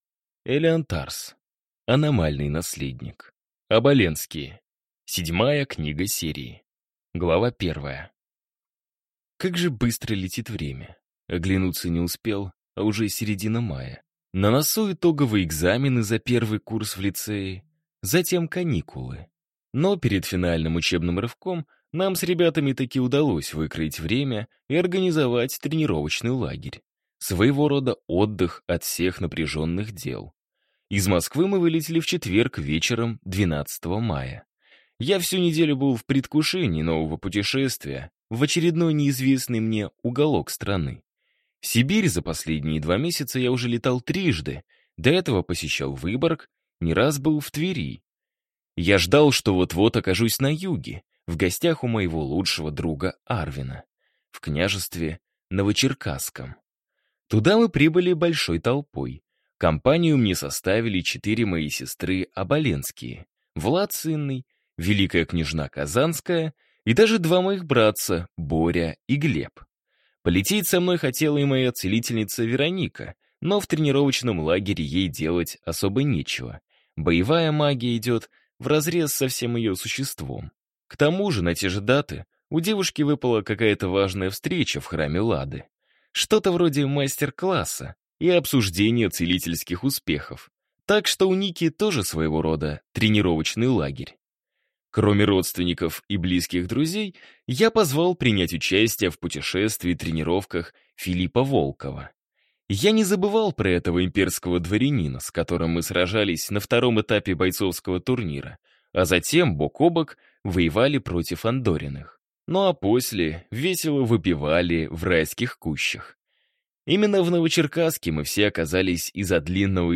Аудиокнига Аномальный Наследник. Оболенские | Библиотека аудиокниг